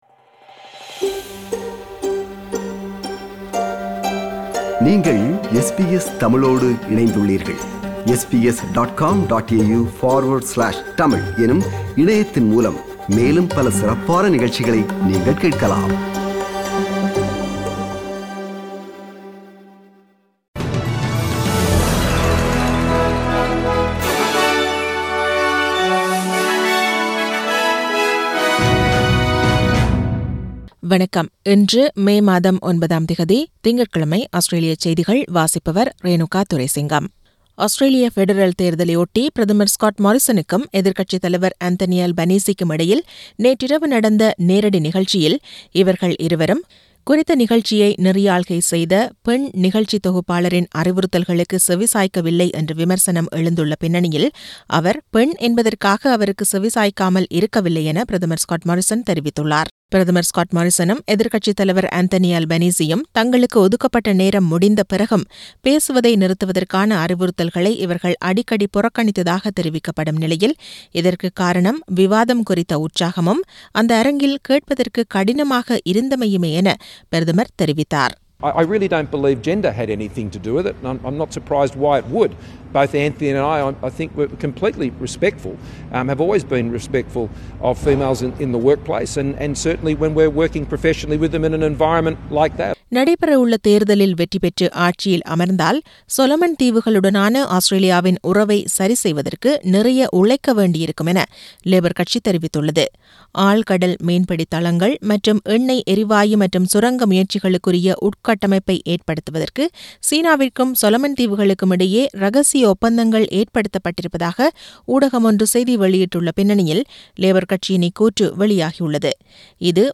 Australian news bulletin for Monday 9 May 2022.